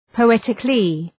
Προφορά
{pəʋ’etıklı}
poetically.mp3